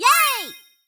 yayS.wav